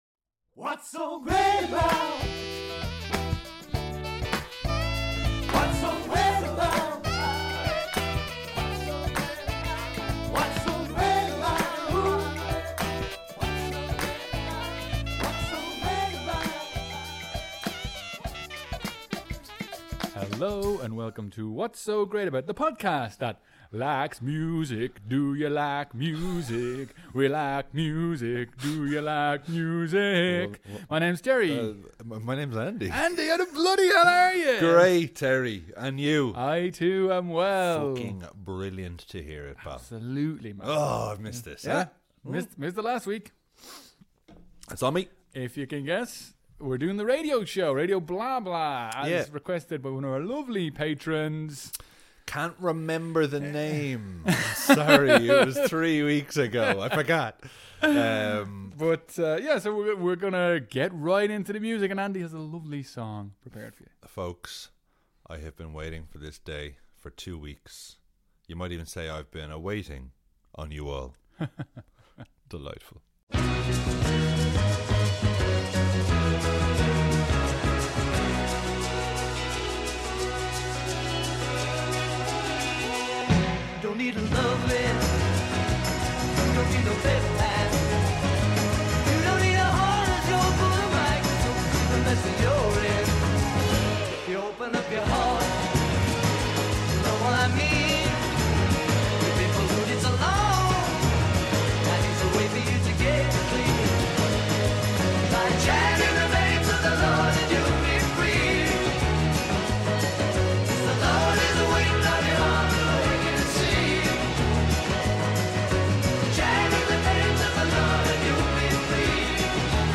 Its a good old NEW WSGA radio show! We chat about what we've been up to, and we're playing some bangers!